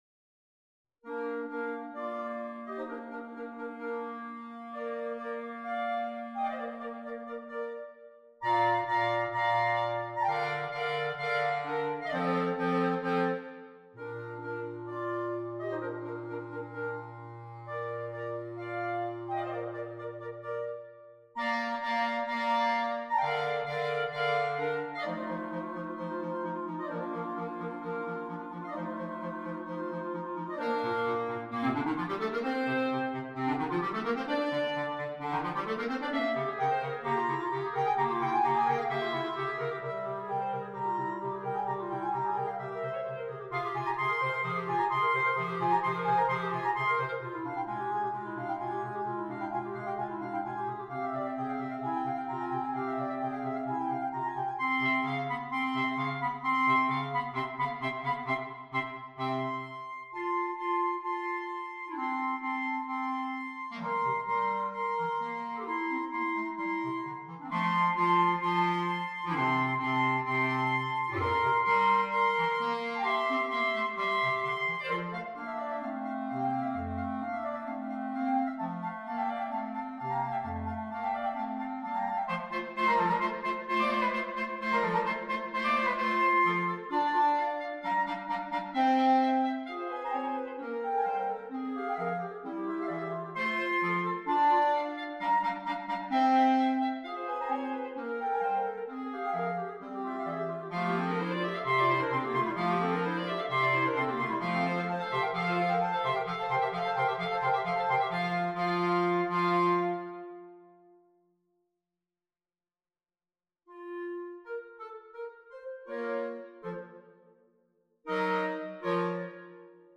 Ouverture per quartetto di clarinetti